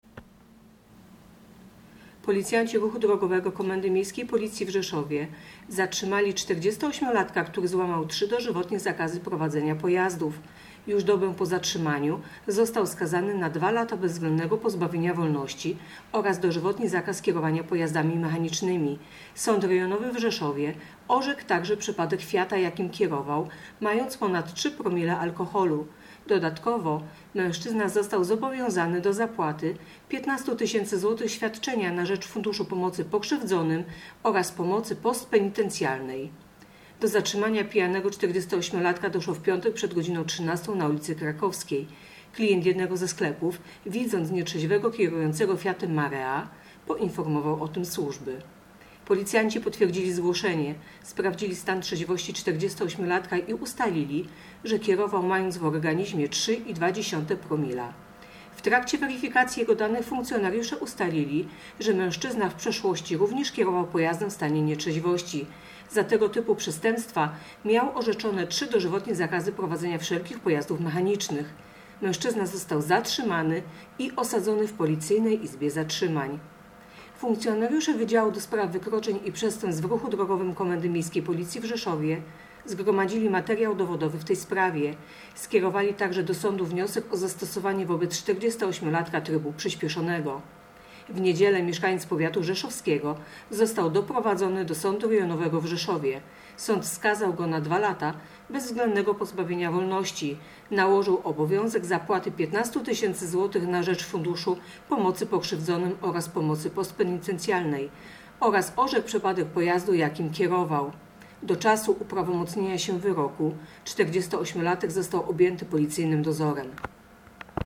Opis nagrania: Informacja pt. 48-latek kierował mając ponad 3 promile, a do tego trzy dożywotnie zakazy. Usłyszał wyrok w trybie przyśpieszonym